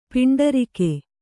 ♪ piṇḍarike